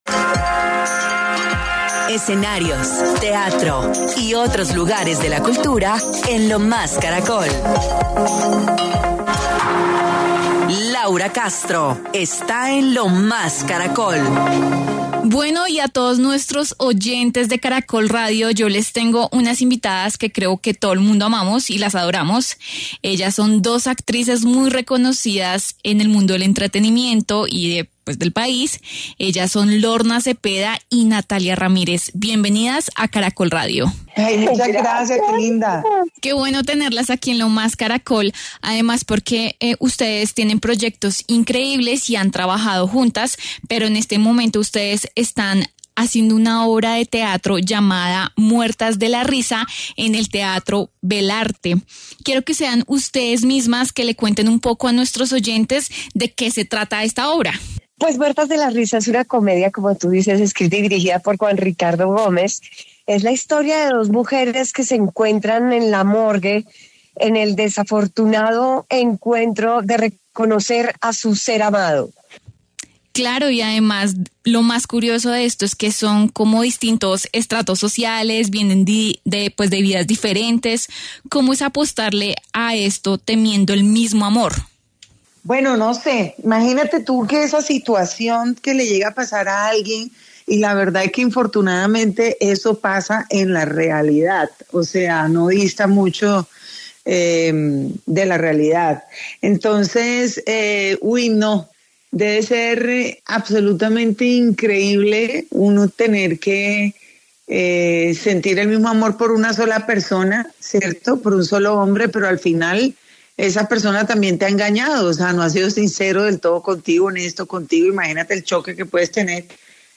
En diálogo con Lo Más Caracol, dieron a conocer como una situación de la vida cotidiana, puede ser transformada en risas y dejar una moraleja, “cada personaje tiene sus argumentos, pero entre más transcurre la historia ambas se dan cuenta que es una batalla perdida por un hombre.